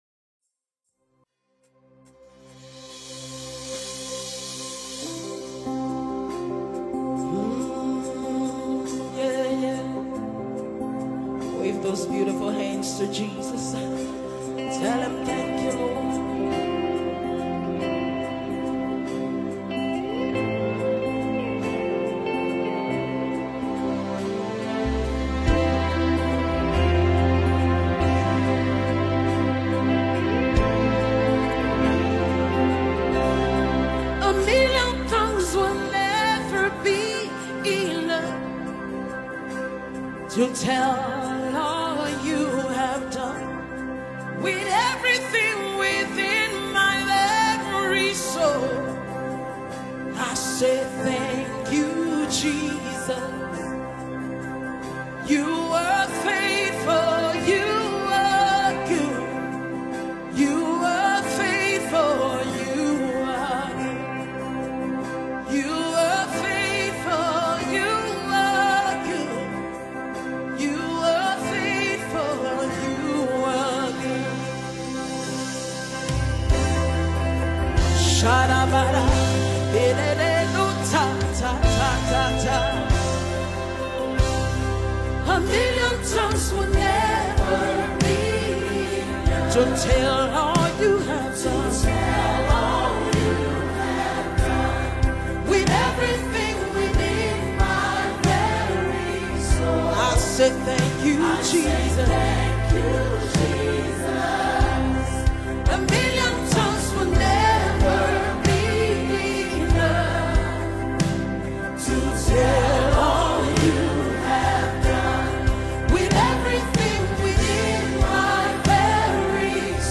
thanksgiving song
gospel singer